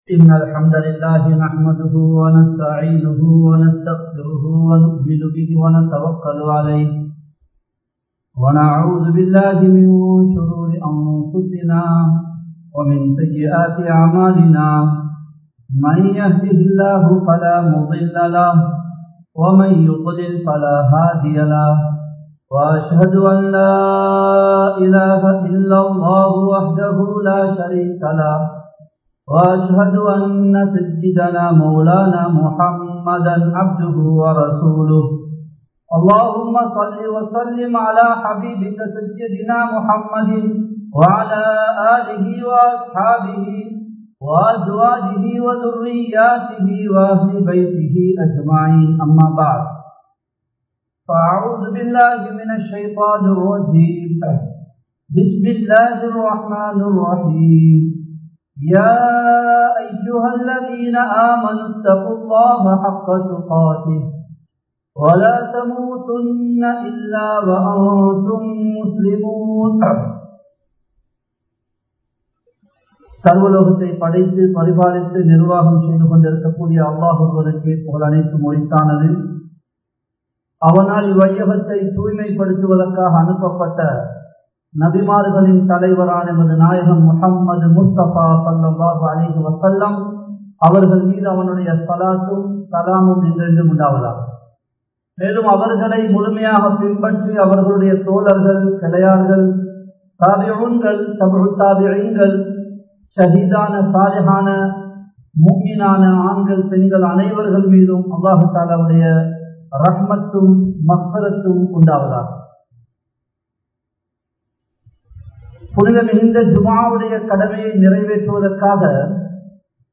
அல்லாஹ்வை நினைவு கூறுவோம் | Audio Bayans | All Ceylon Muslim Youth Community | Addalaichenai
Dehiwela, Muhideen (Markaz) Jumua Masjith